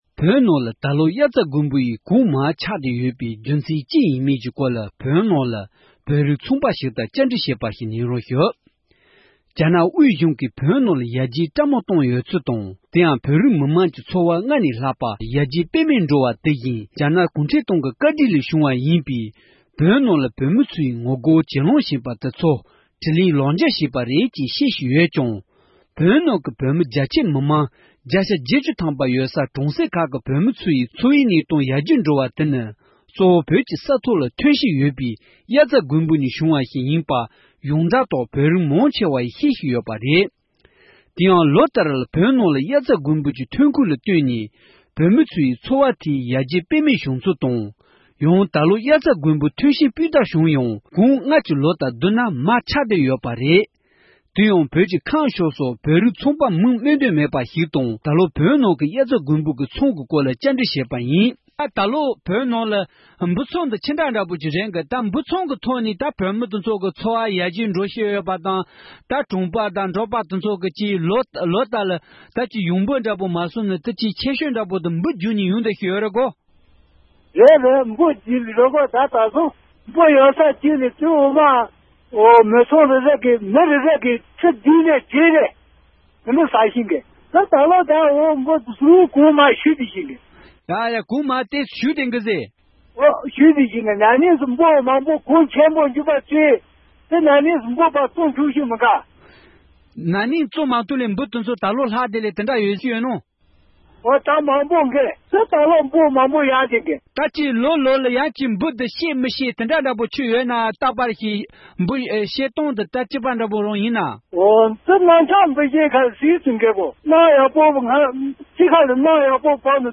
བོད་རིགས་ཚོང་པ་ཞིག